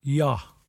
[ye-A-h]